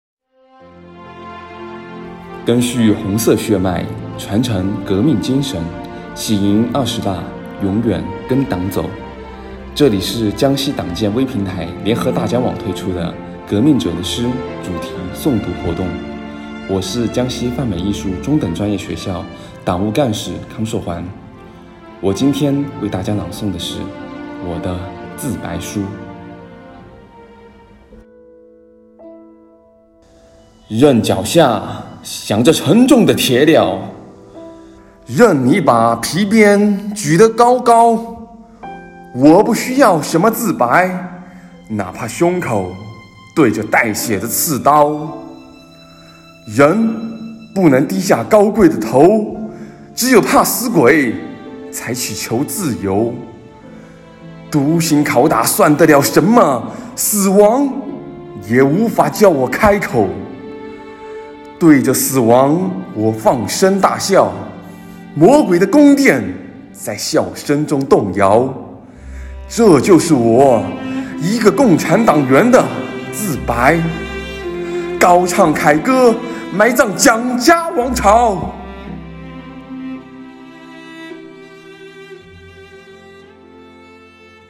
即日起，江西泛美艺术中等专业学校微信公众号开设〔革命者的诗主题诵读〕专栏，通过视频或音频的形式，刊发我校党员教师的诵读作品，今天推出第二期：陈然《我的“自白”书》。